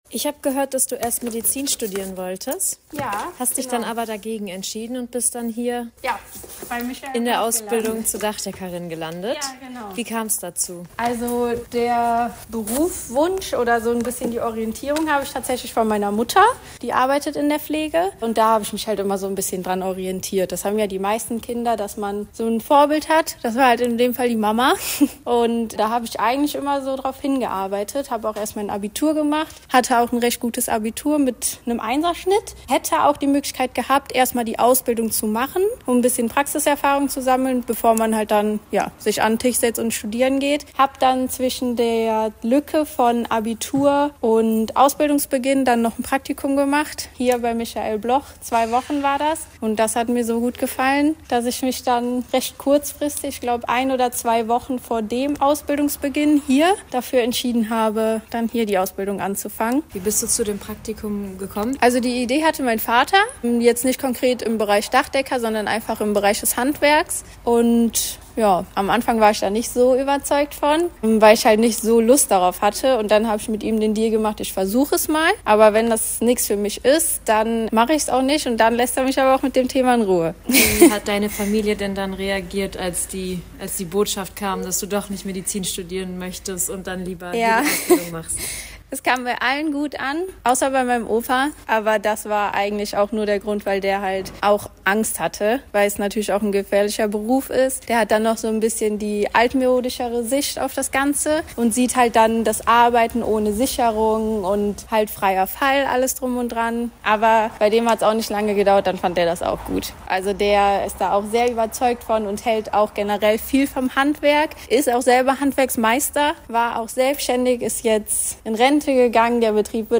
Radiobeiträge: Frauen im Handwerk